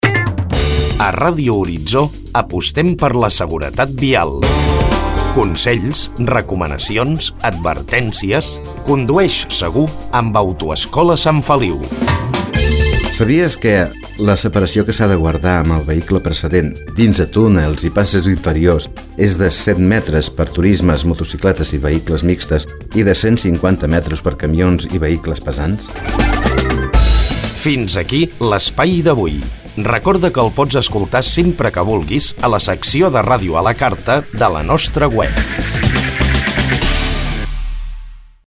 Comercial
Divulgació